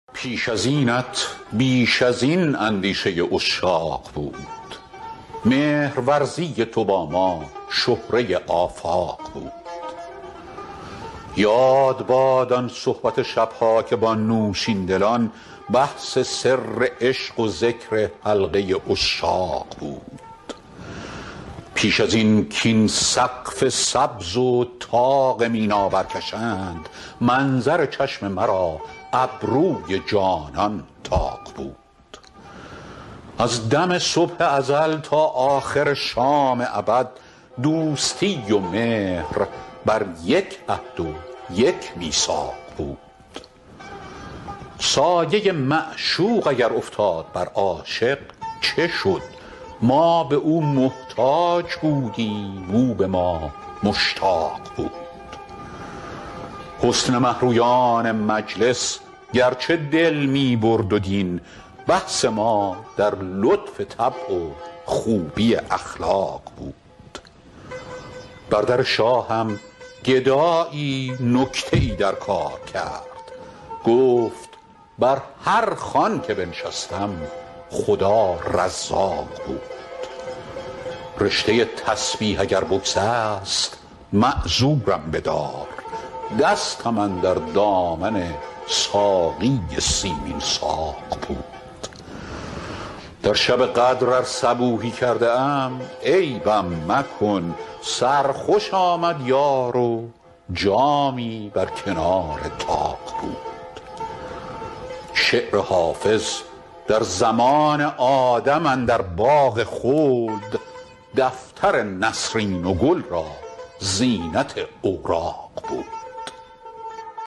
حافظ غزلیات غزل شمارهٔ ۲۰۶ به خوانش فریدون فرح‌اندوز